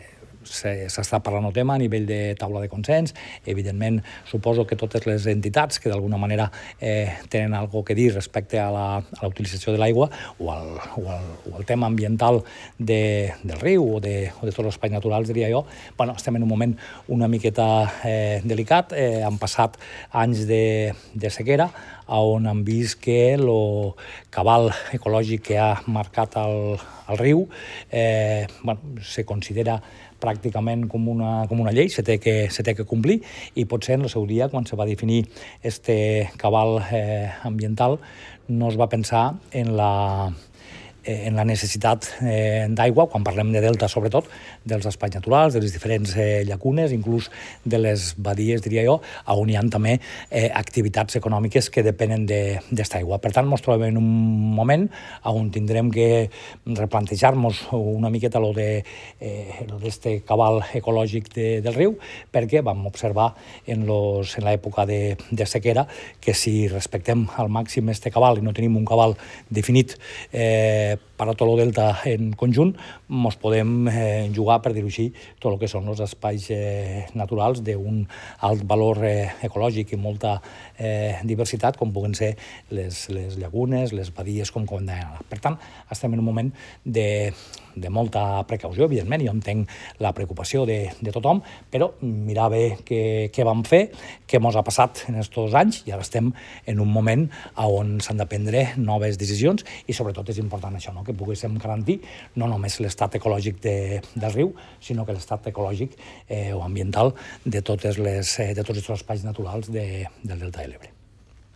El delegat del Govern a les Terres de l’Ebre, Joan Castor Gonell
El-delegat-del-Govern-a-les-Terres-de-lEbre-Joan-Castor-Gonell.mp3